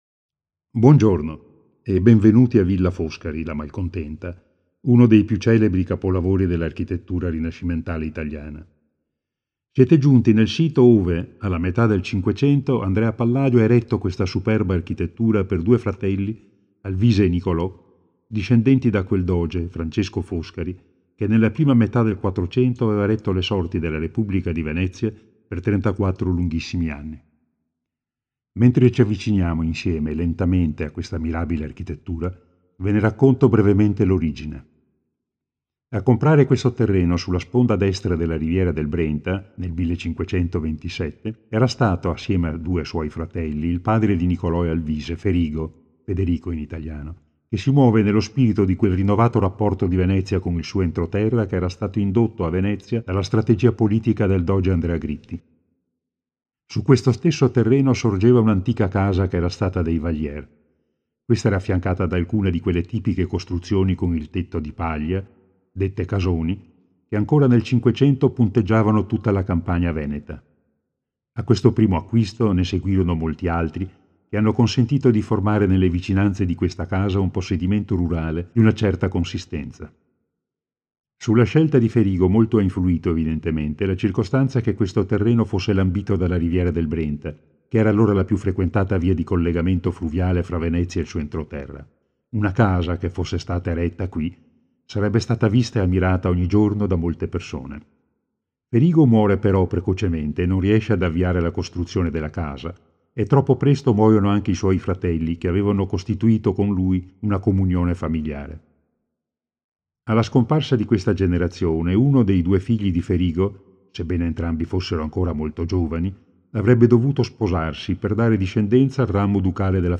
Audioguida (3:55)